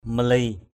/mə-li:/ Mali ml} [Cam M] (d.) xứ Lagi = pays de Lagi = countries Lagi.